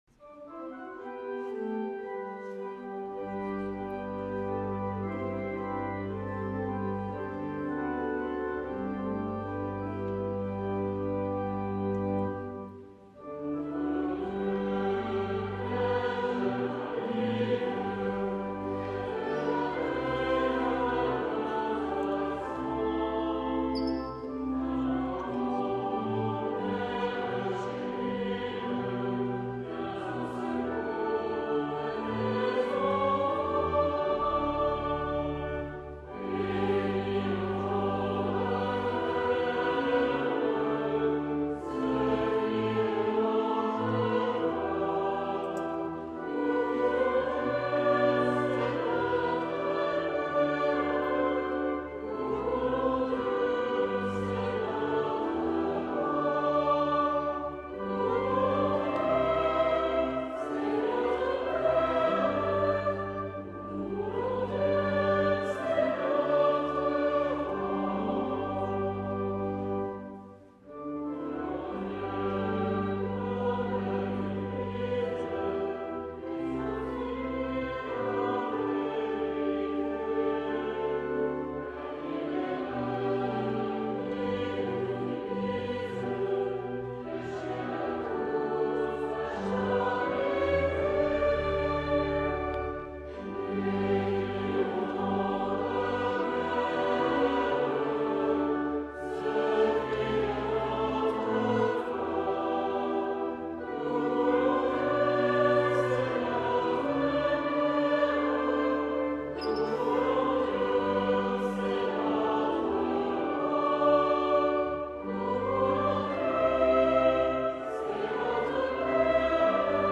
2020 CHANTS D'ÉGLISE audio closed https